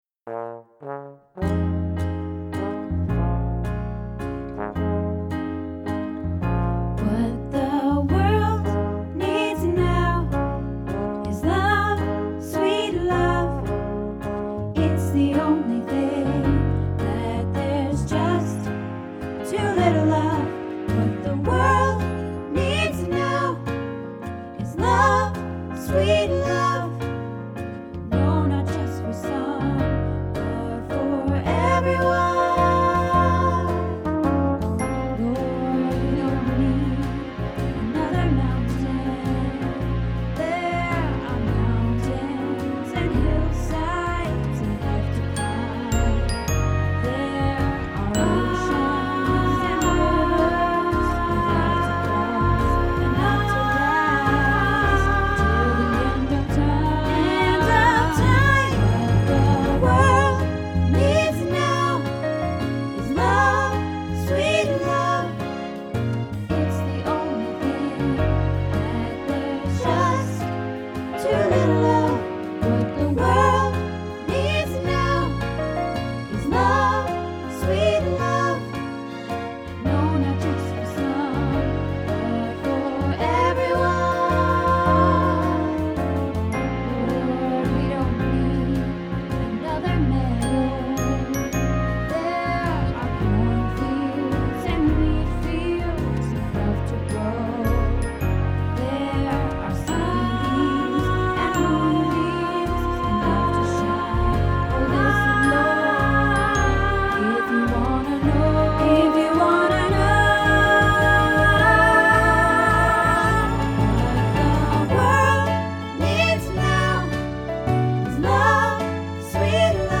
What the World Needs Now -Soprano